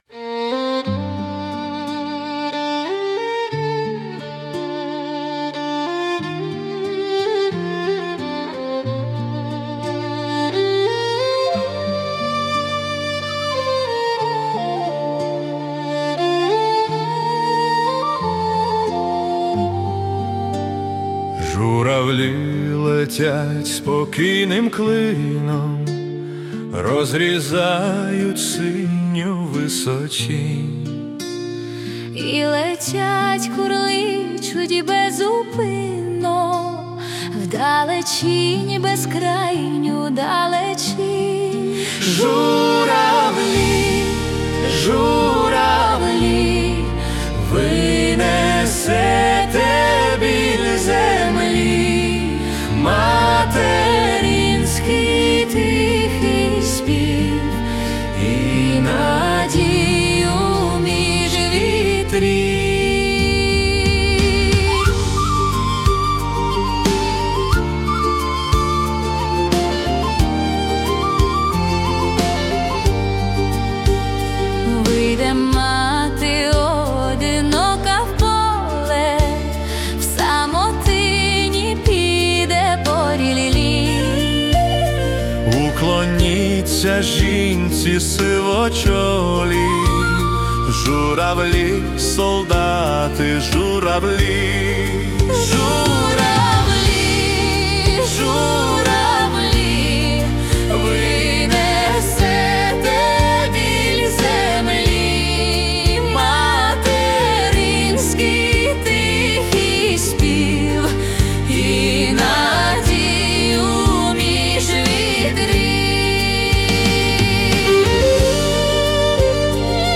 Ukrainian Ballad / Estrada